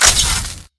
damaged.ogg